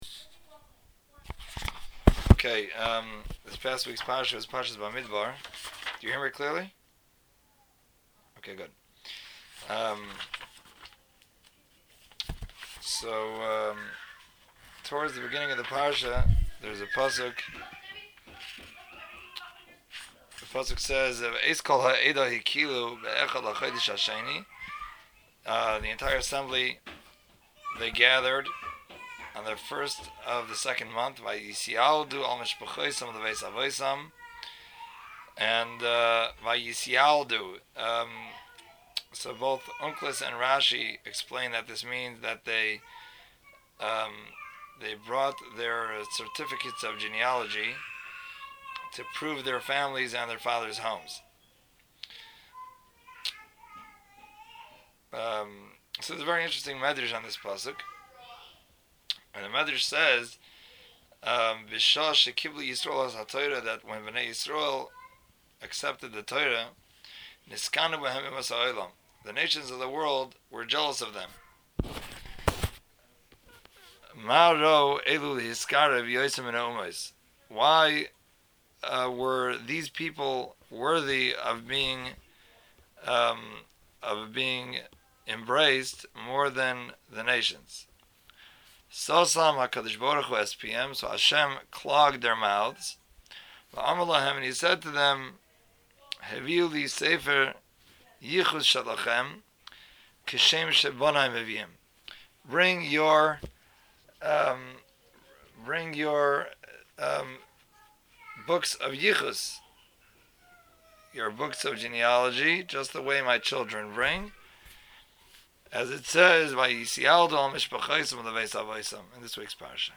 Bamidbar-Drasha-5770.mp3